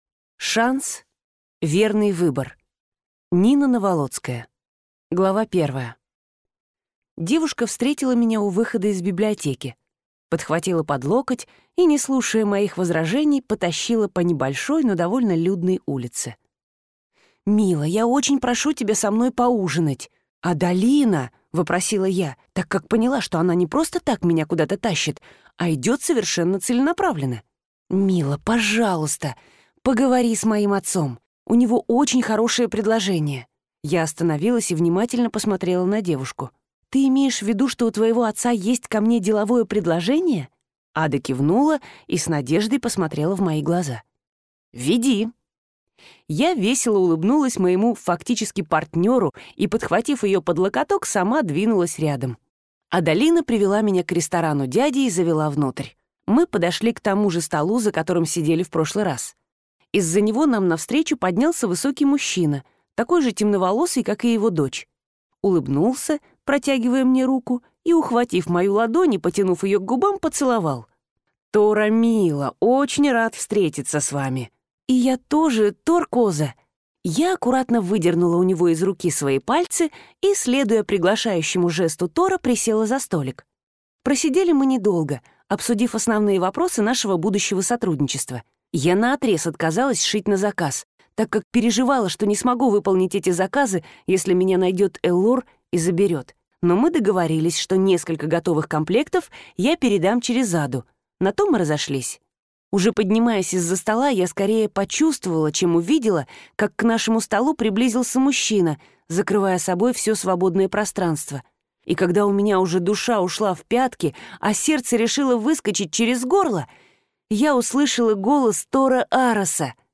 Аудиокнига Шанс. Верный выбор | Библиотека аудиокниг